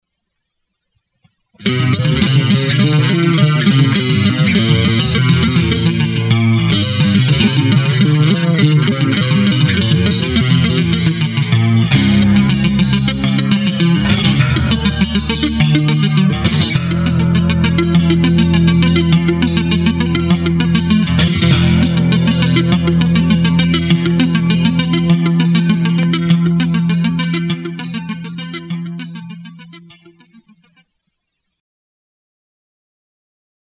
NOTE: bass solo